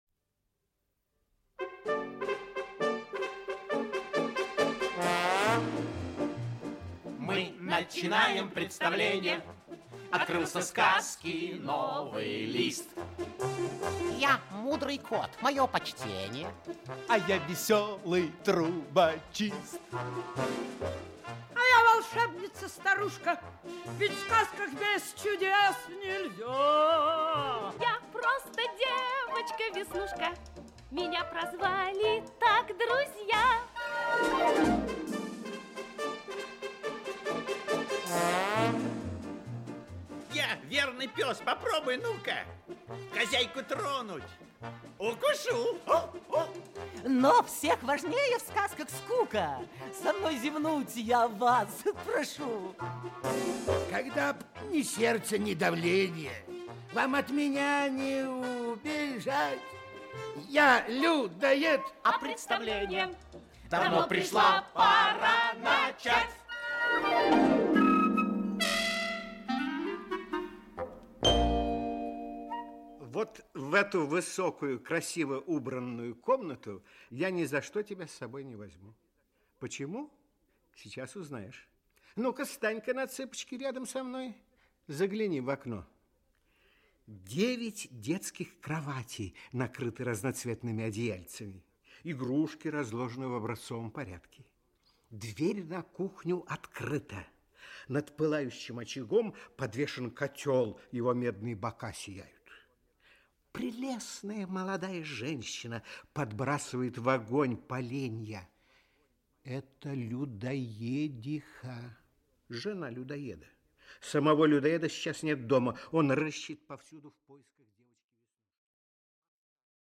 Аудиокнига Счастливый конец. Часть 3 | Библиотека аудиокниг
Часть 3 Автор Екатерина Борисовна Борисова Читает аудиокнигу Актерский коллектив.